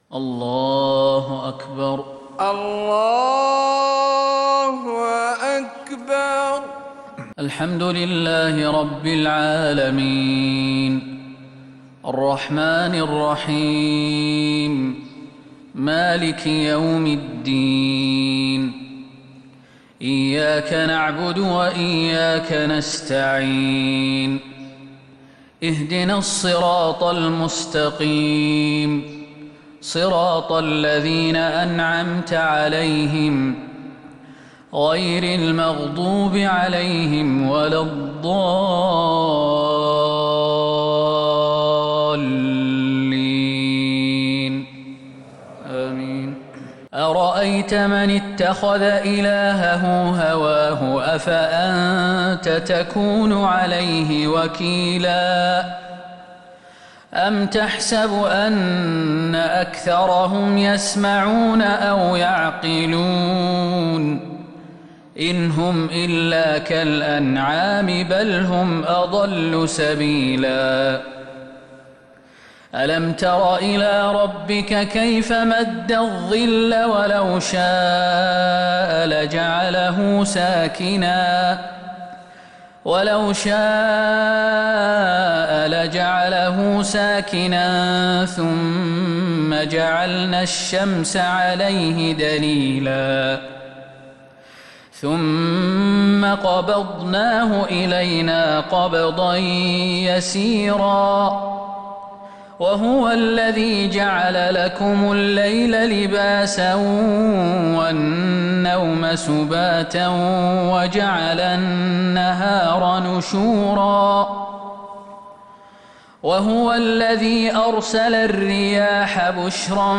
صلاة الفجر للقارئ خالد المهنا 7 محرم 1442 هـ